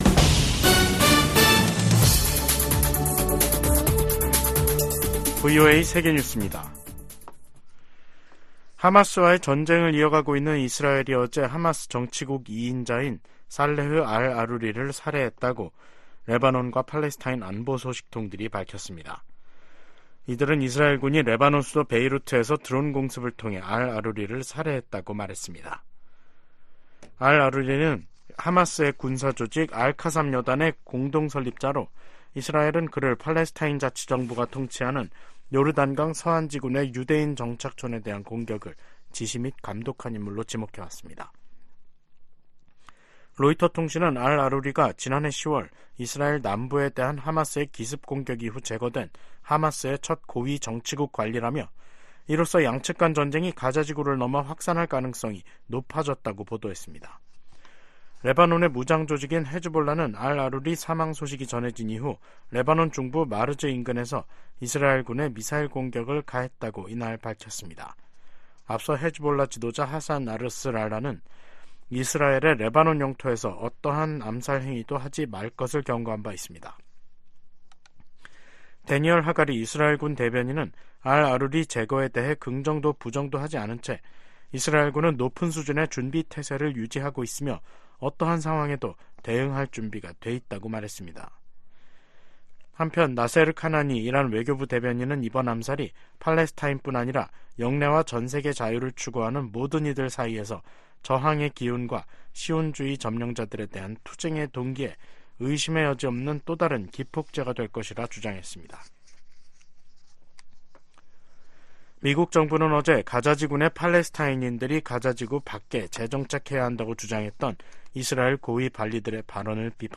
세계 뉴스와 함께 미국의 모든 것을 소개하는 '생방송 여기는 워싱턴입니다', 2024년 1월 3일 저녁 방송입니다. '지구촌 오늘'에서는 하마스 최고위급 인사가 이스라엘에 암살당한 소식 전해드리고, '아메리카 나우'에서는 의사당 난입 사태 후 약 3년, 미국인들이 도널드 트럼프 전 대통령과 지지자들에 더 동조하고 있으며 지난 대선이 부정 선거였다고 생각하는 시각이 더 많아졌음을 보여주는 여론조사 결과 살펴보겠습니다.